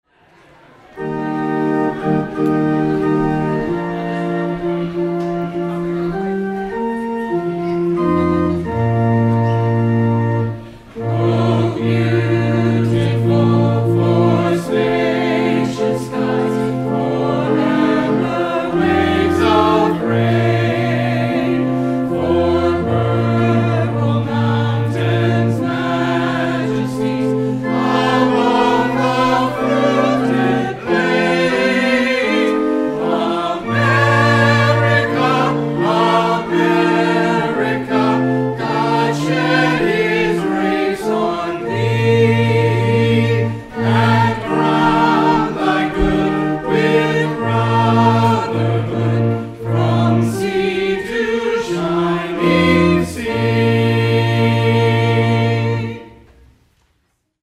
Performed by the Algiers UMC Choir